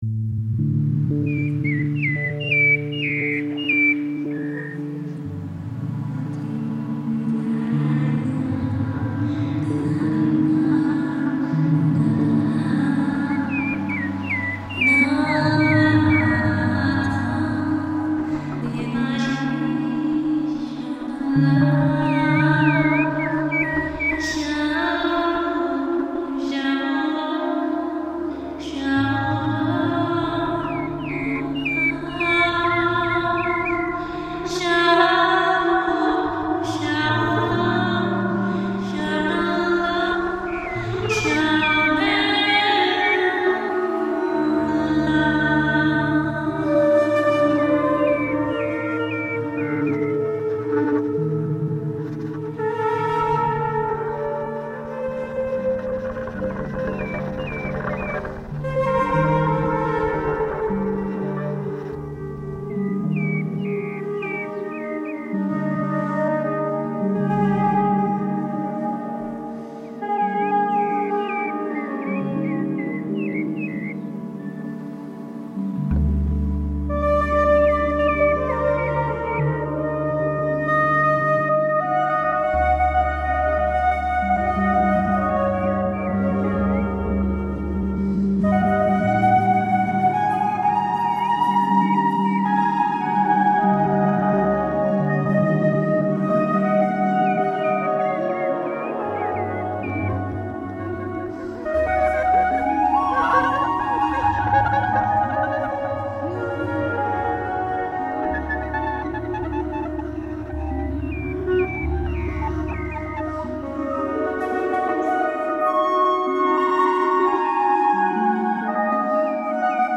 The field recording from India immediately revealed itself to me as a form of male ritual music, rooted in repetition, gesture, and collective memory.
The opening unfolds in a dreamlike, immersive space, imagined as a temple.
flute
double bass
Through a slow and carefully sculpted crescendo, these elements open the way to the rhythmic core of the piece: the field recording itself. At this point, the music shifts from inward reflection to outward movement, inviting the body into dance and tribal motion.